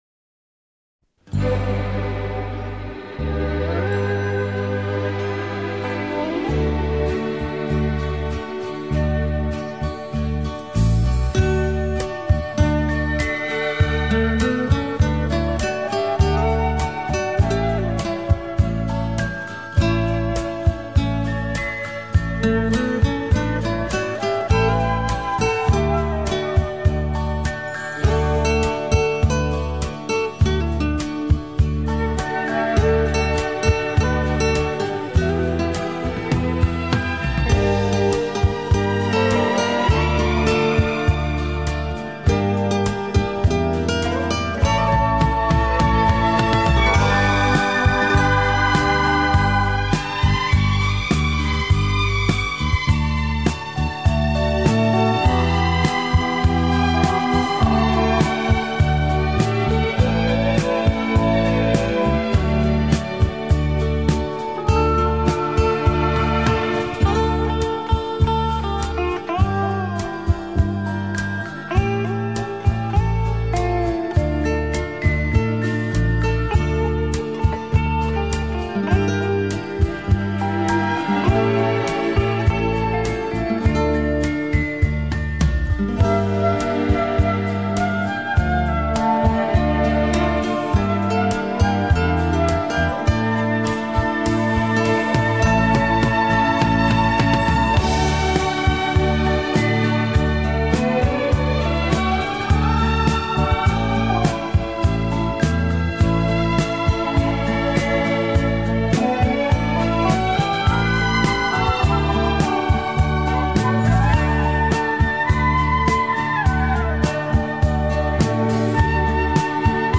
شور انگیز!